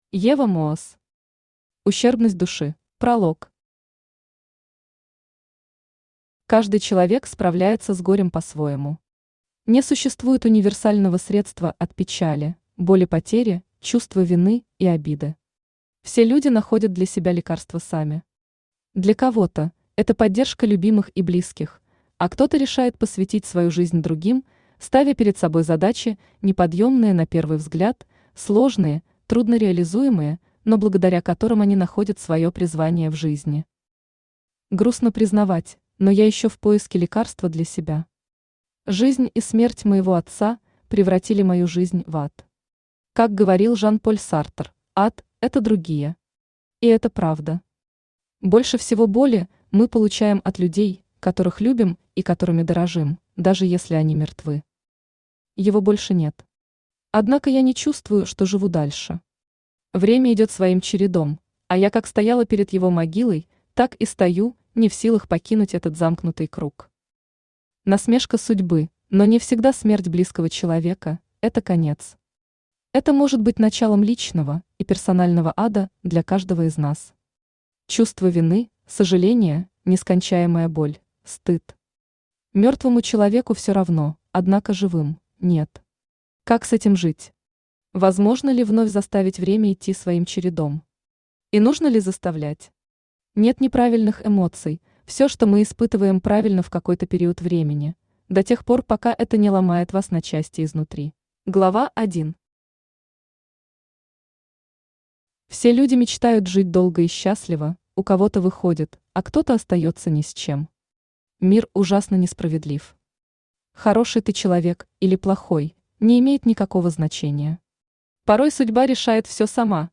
Аудиокнига Ущербность души | Библиотека аудиокниг
Aудиокнига Ущербность души Автор Ева Моос Читает аудиокнигу Авточтец ЛитРес.